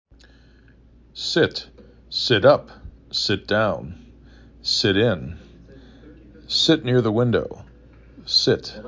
sit 3 /s/ /i/ /t/ Frequency: 772
s i t